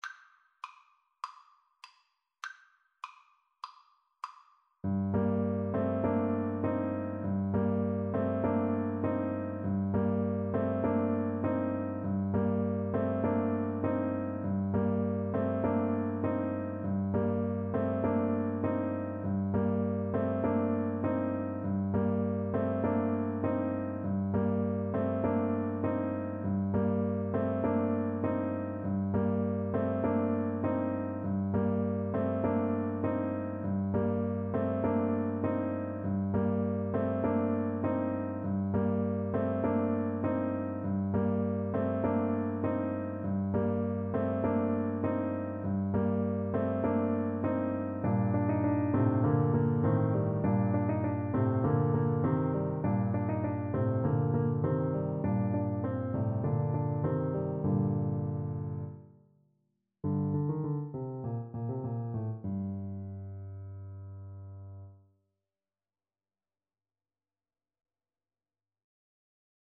Allegro (View more music marked Allegro)
4/4 (View more 4/4 Music)
Classical (View more Classical Clarinet Music)